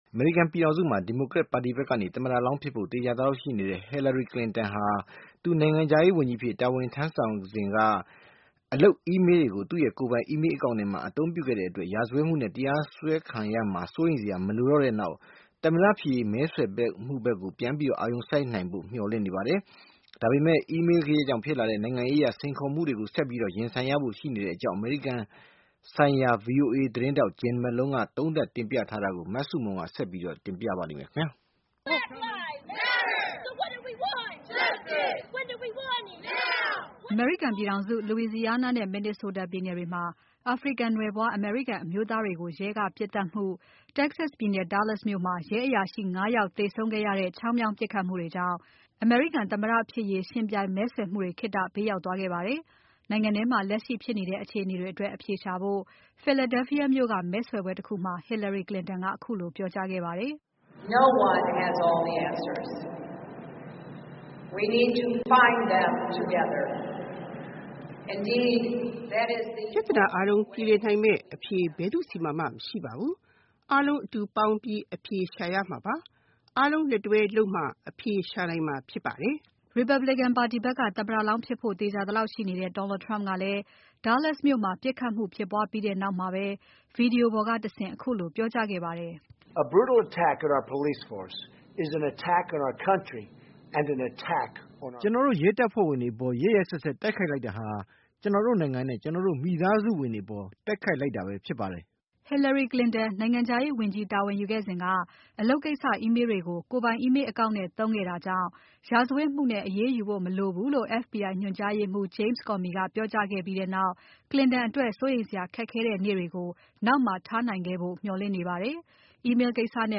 by ဗွီအိုအေသတင်းဌာန